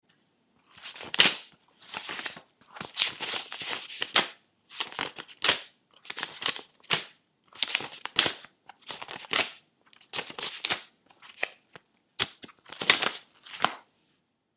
HOJAS LIBRO
Tonos gratis para tu telefono – NUEVOS EFECTOS DE SONIDO DE AMBIENTE de HOJAS LIBRO
hojas_libro.mp3